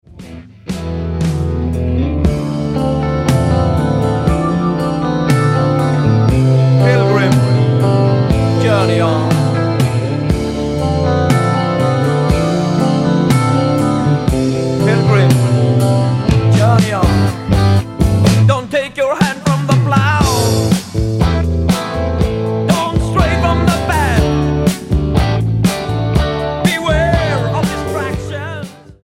STYLE: Hard Music
the third album from Sweden's rock/metal band